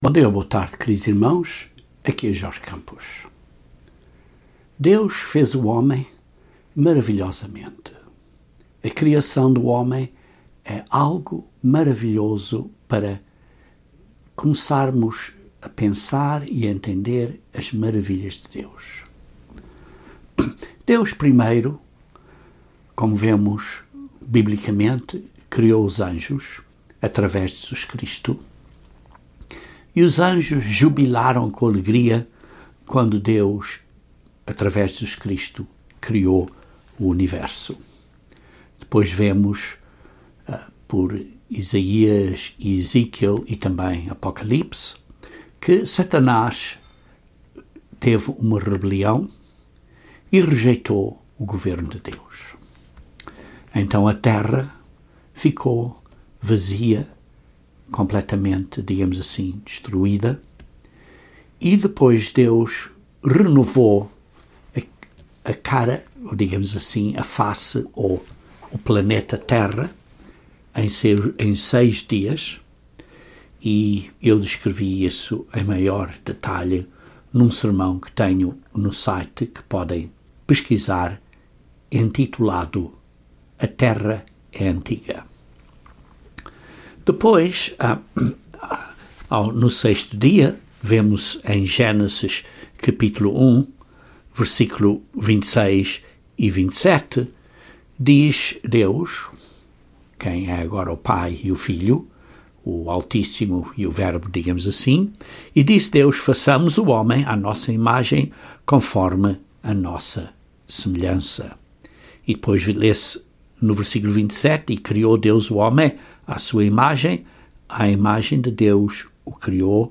Este sermão aborda o tema do espírito do homem no homem (espírito humano) e como é “uma ponte” entre a vida física e virmos a ser filhos e filhas de Deus na Família Deus.